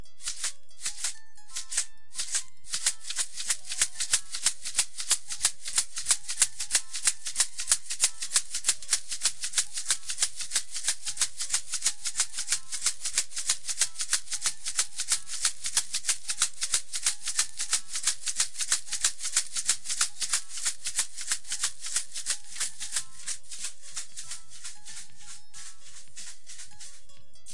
发现 " 摇蛋器02
描述：Shaker打击乐器自制
标签： 振动筛 打击乐 国产
声道立体声